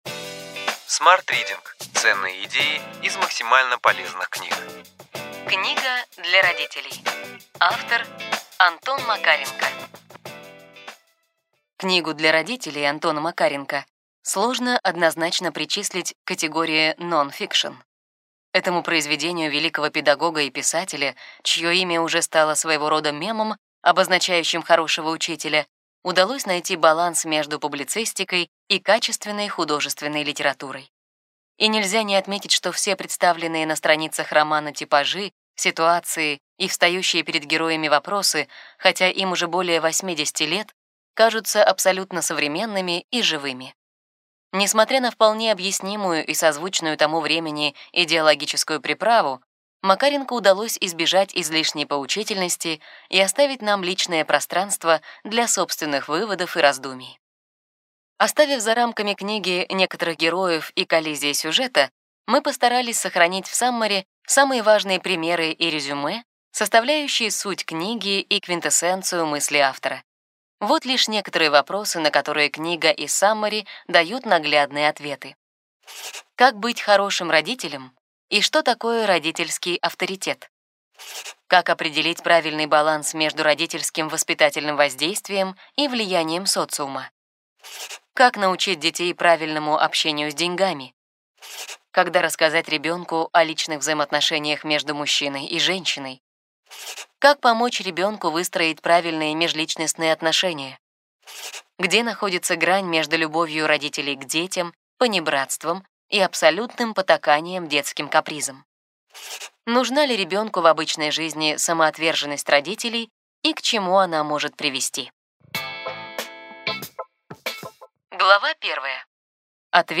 Аудиокнига Ключевые идеи книги: Книга для родителей.